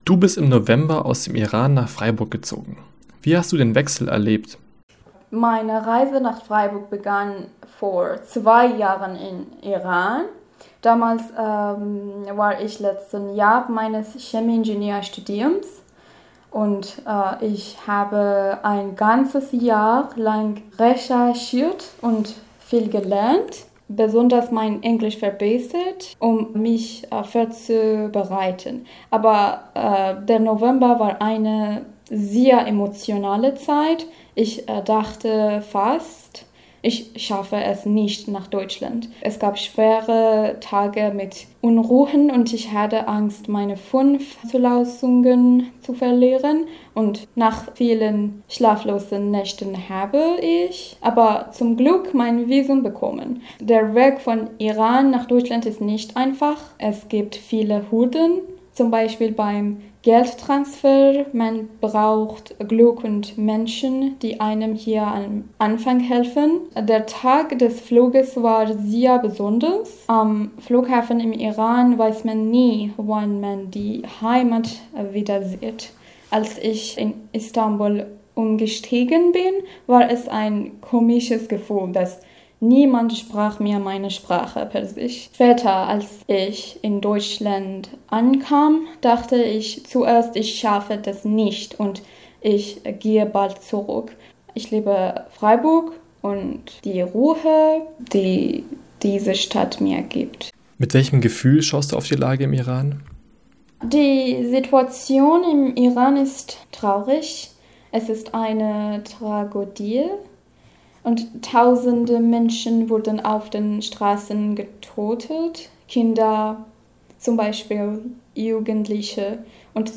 Interview mit Studentin aus dem Iran: "Dankbar für das Glück, das mich nach Freiburg geführt hat"